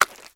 STEPS Swamp, Walk 14.wav